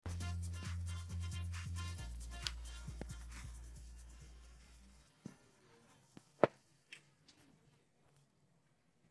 Background Music
comp1_no_vocals_bce1e8.mp3